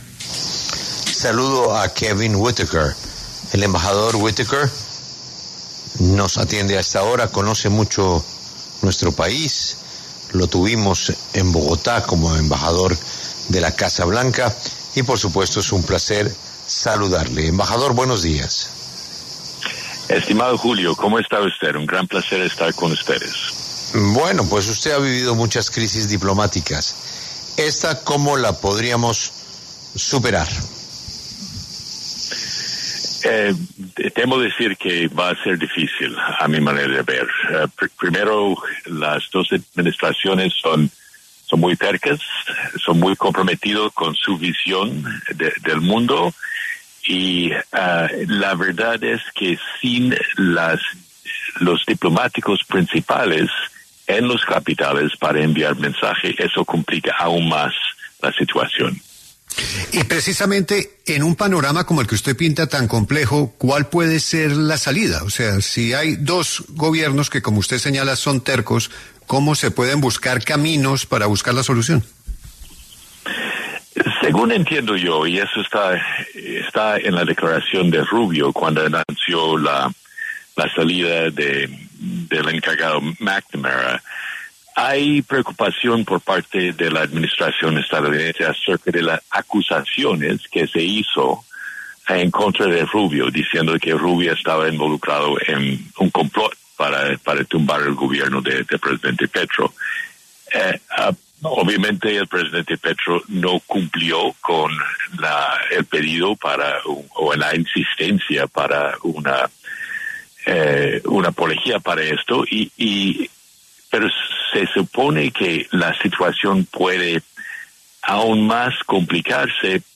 Kevin Whitaker, exembajador de EEUU en Colombia, pasó por los micrófonos de La W, con Julio Sánchez Cristo, y compartió un análisis sobre la situación actual de tensiones diplomáticas entre ambos países.